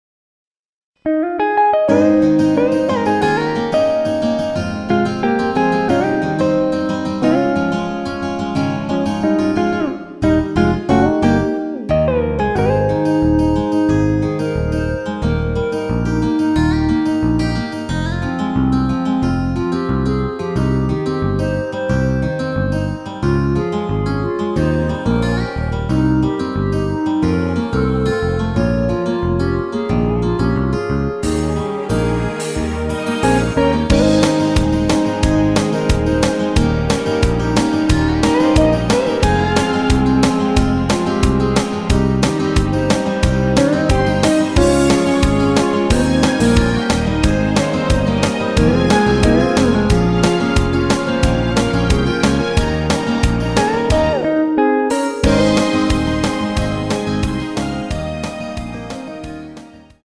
원키에서(-2)내린 멜로디 포함된 MR 입니다.(미리듣기 확인)
Ab
앞부분30초, 뒷부분30초씩 편집해서 올려 드리고 있습니다.
중간에 음이 끈어지고 다시 나오는 이유는